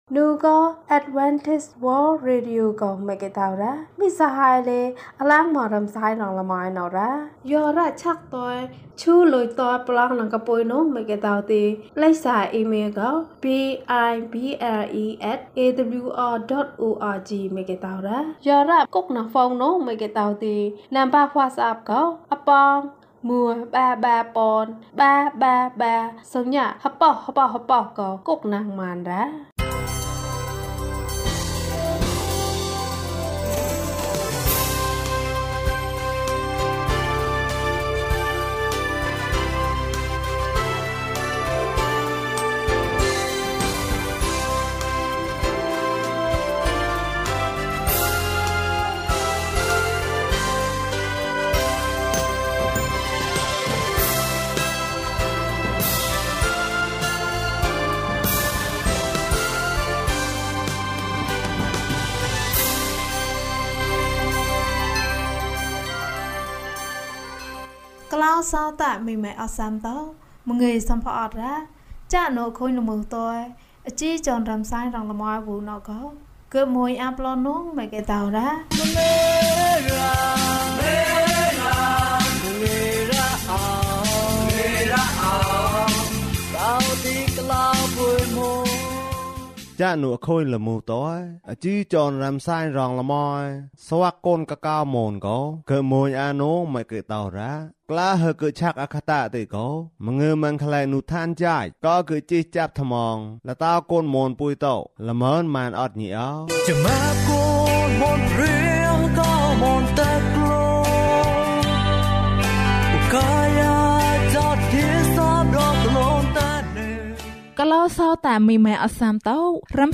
ဘုရားသခင်သည် ကျွန်ုပ်တို့၏ဆုတောင်းချက်ကို နားထောင်ပါ။ ကျန်းမာခြင်းအကြောင်းအရာ။ ဓမ္မသီချင်း။ တရားဒေသနာ။